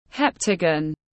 Hình thất giác tiếng anh gọi là heptagon, phiên âm tiếng anh đọc là /ˈhep.tə.ɡən/.
Heptagon /ˈhep.tə.ɡən/